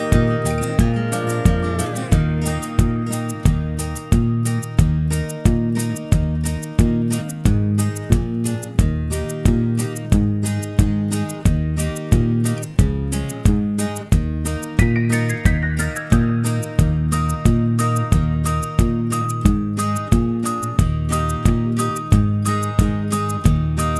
no Backing Vocals Country (Female) 2:20 Buy £1.50